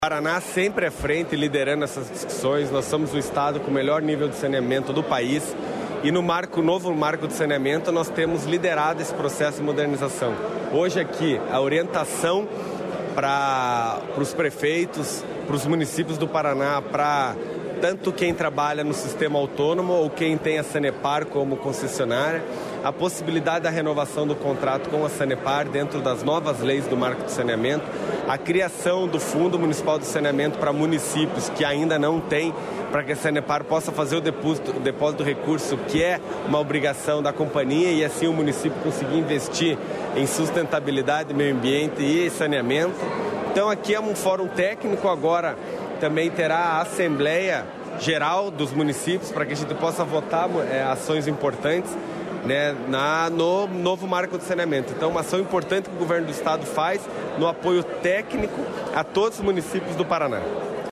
Sonora do secretário das Cidades, Eduardo Pimentel, sobre contratos com a Sanepar